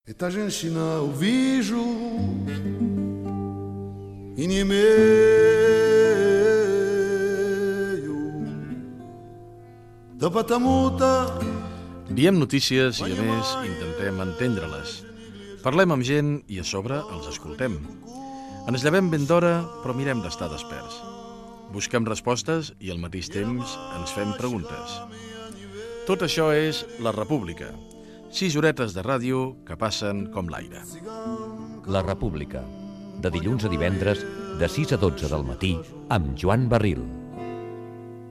Promoció del programa
Info-entreteniment